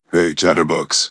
synthetic-wakewords
ovos-tts-plugin-deepponies_Kratos_en.wav